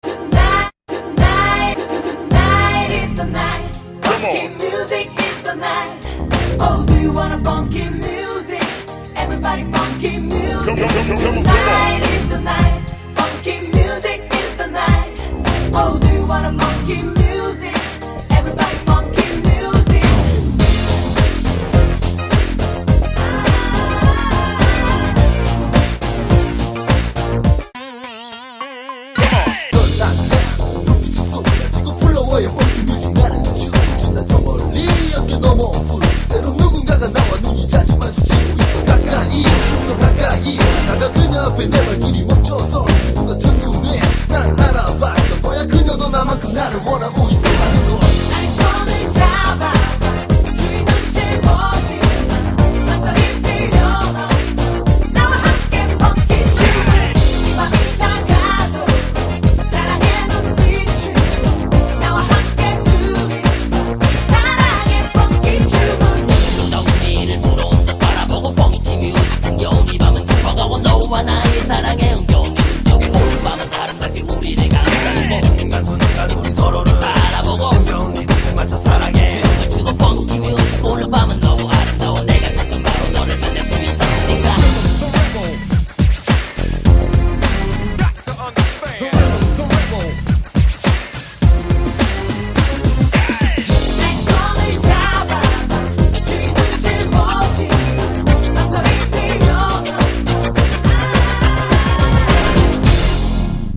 BPM105--1
Audio QualityPerfect (High Quality)